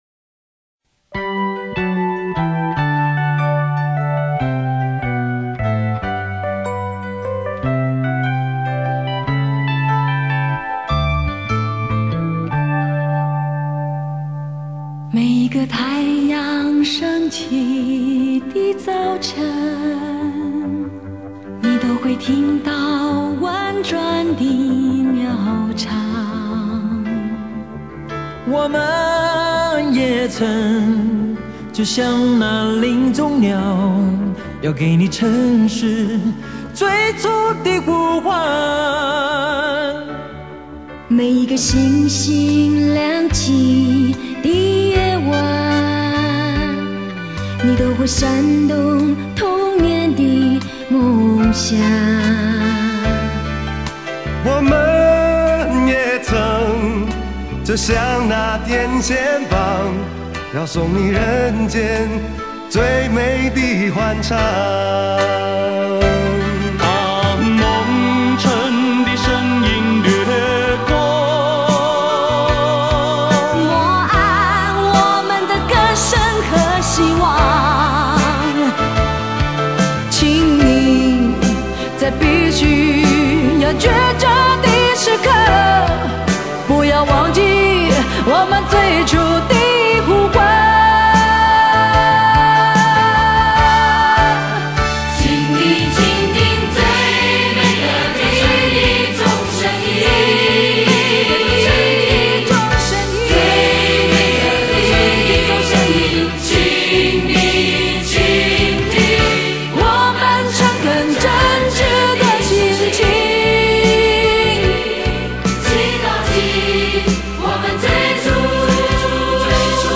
也只有主题性的大型合唱的撮合下，
当时为了IFPI反盗版活动所邀集各大唱片公司旗下大牌艺人合唱的一首歌曲。